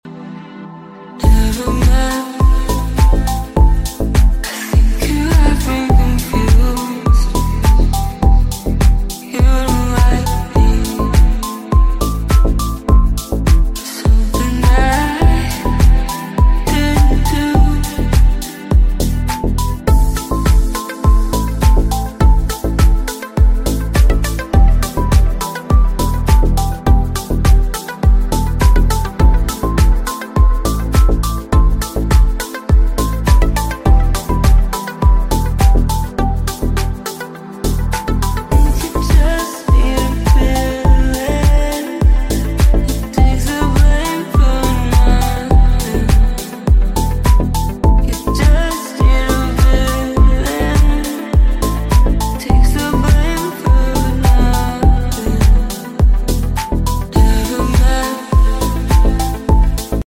Best Sad TikTok Audio